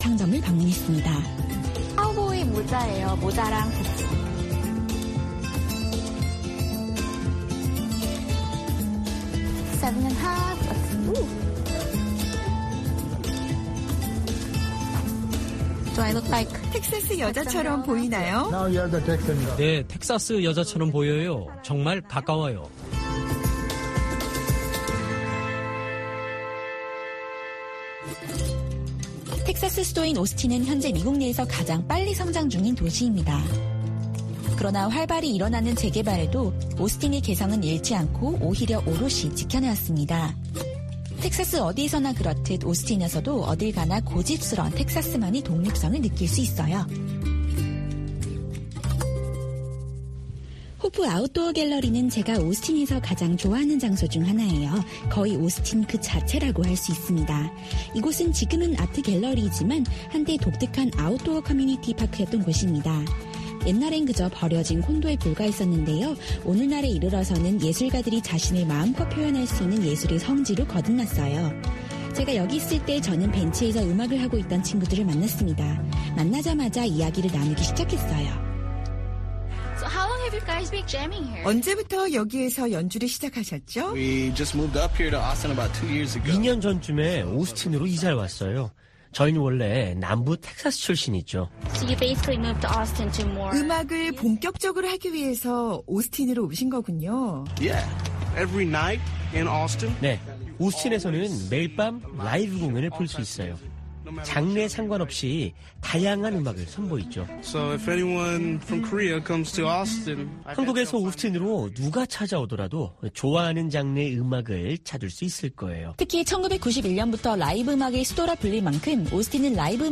VOA 한국어 방송의 일요일 오전 프로그램 2부입니다.